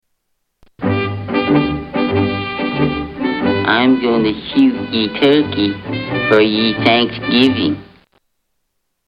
I am going to shoot ye turkey- Cartoon Pilgrim
Tags: Holiday Thanksgiving TV Sounds Thanksgiving TV Episodes Thanksgiving Day Television